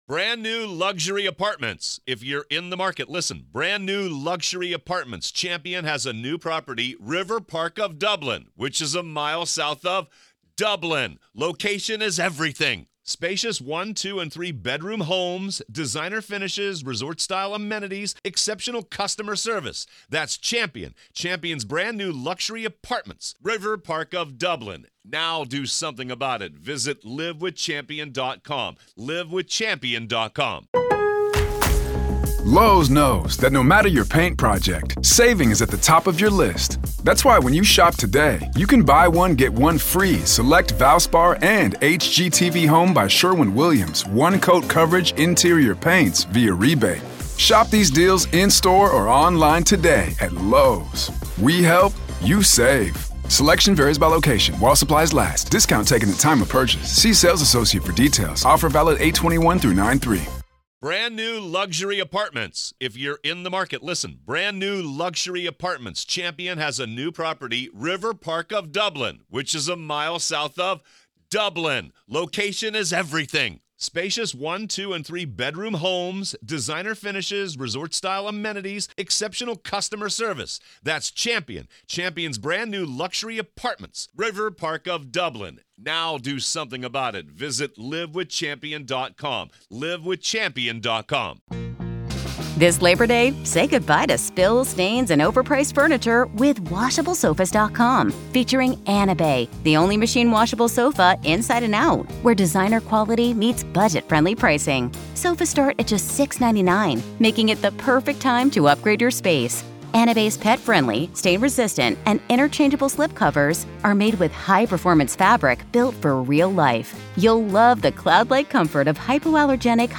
In this revealing conversation